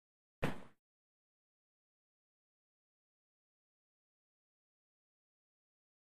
SnowFSMuffled WES095201
Snow Hiking; Muffled Footstep In Snow.